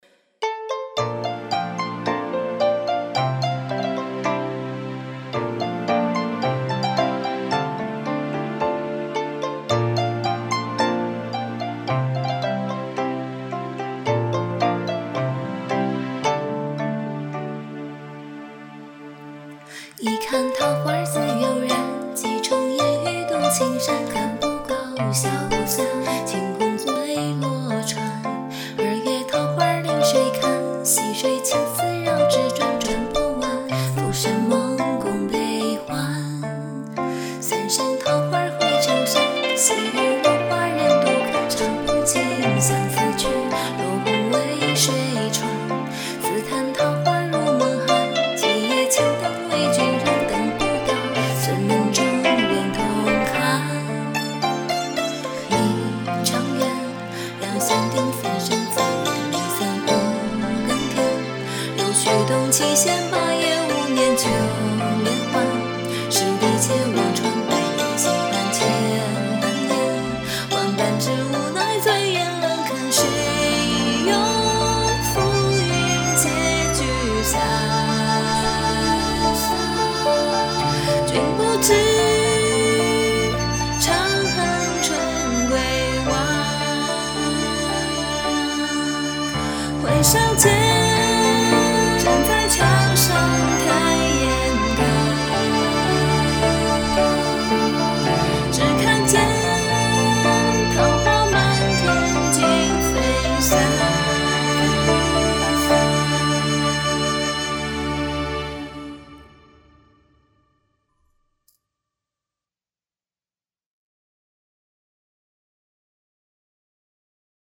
古风音乐 我喜欢。。。